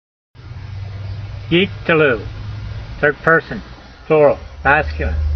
My reading (voice) in modern Israeli style is only good enough to get you started.
yeek-te-lu